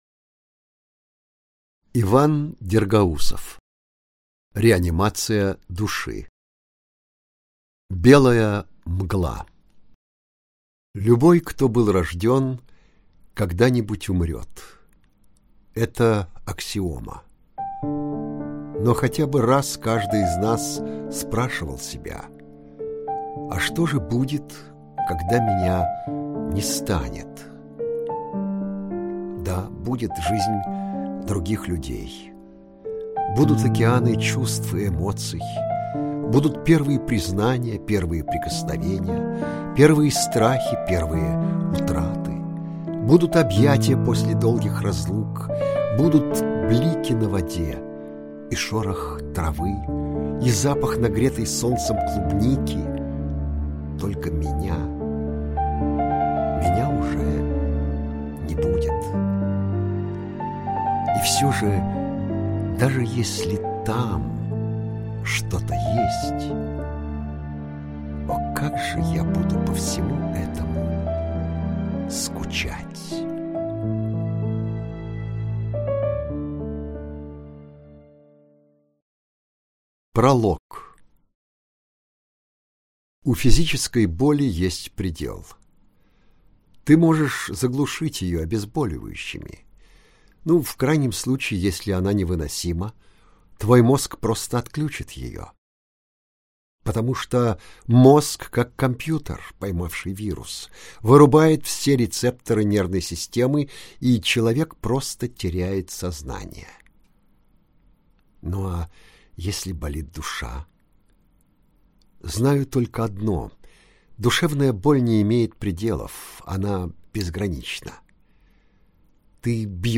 Аудиокнига Реанимация души. Белая мгла | Библиотека аудиокниг